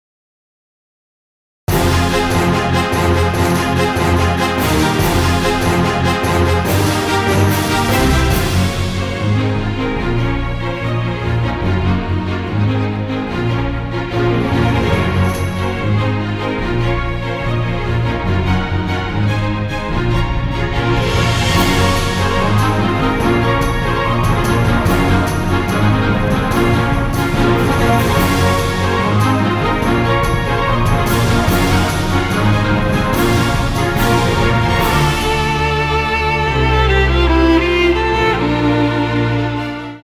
ソロヴァイオリン